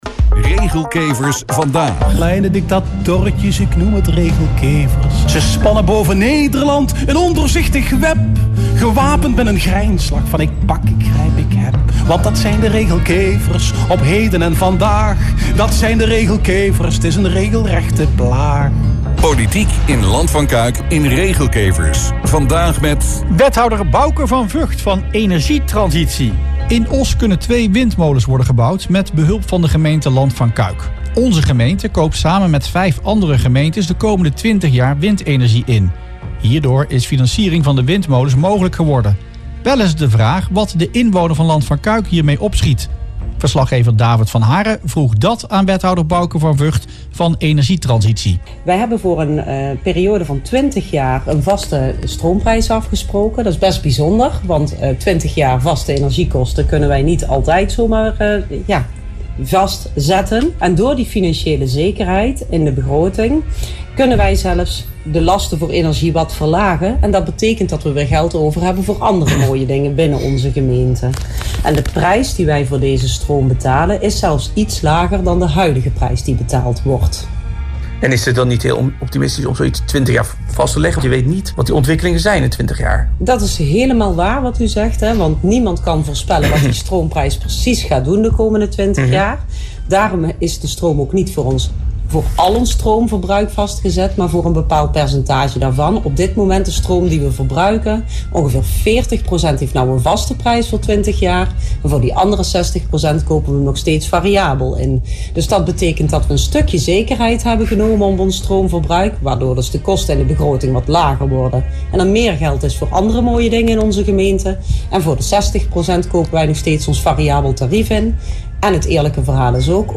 Wethouder Van Vught (energie) in Regelkevers Vandaag